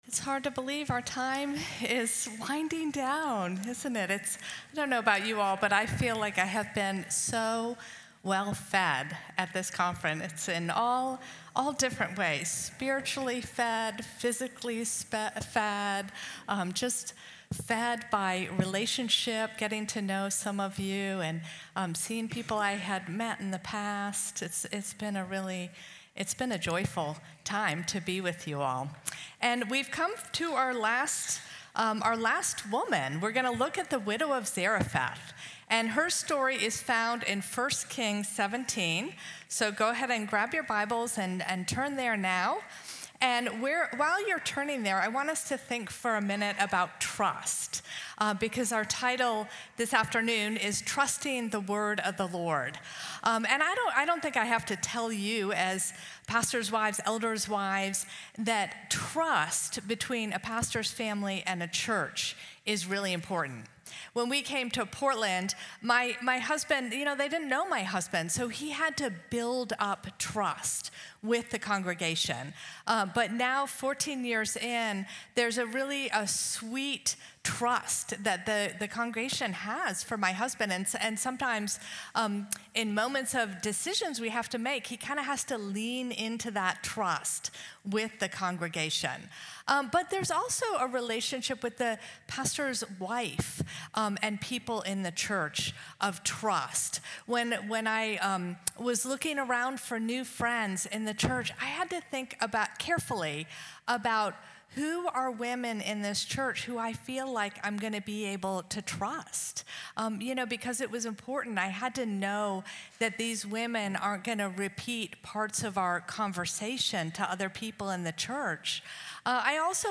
Audio recorded at Feed My Sheep for Pastors Wives Conference 2024.